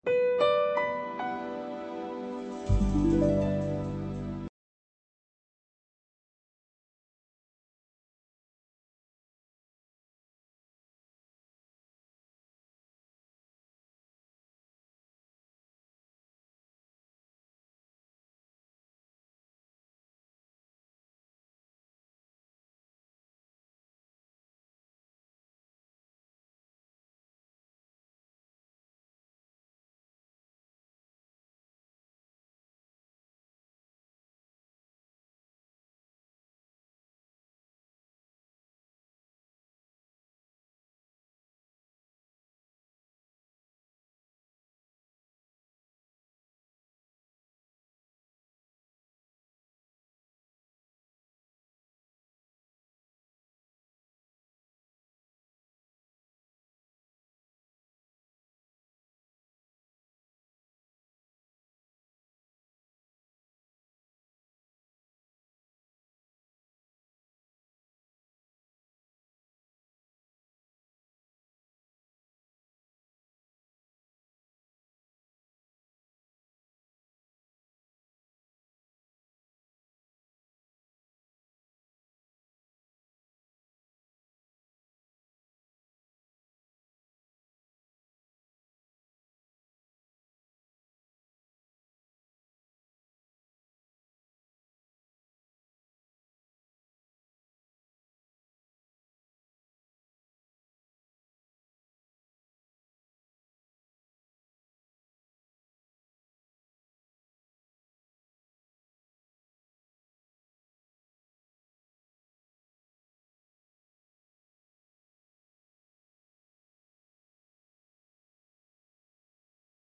Tutoría de Historia Antigua, del Grado de Arte, en la UNED de Pamplona